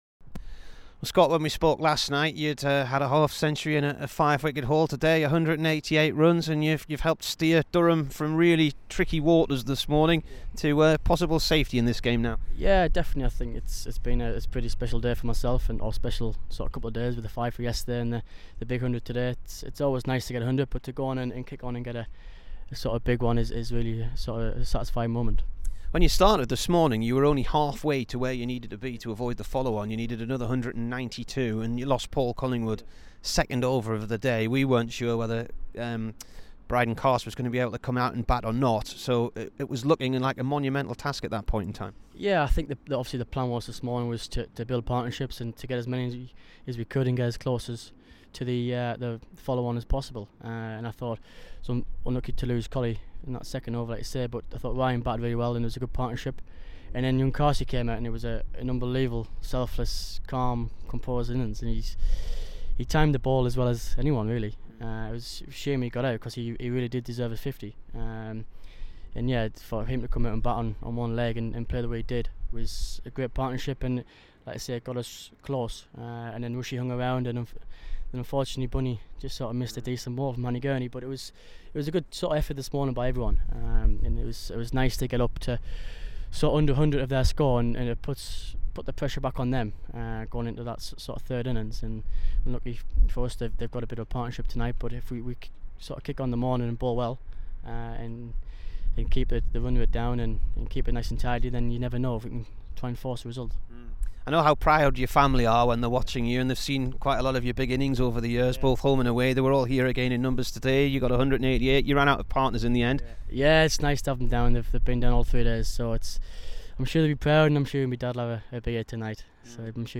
Here is the Durham batsman after his 188 v Notts.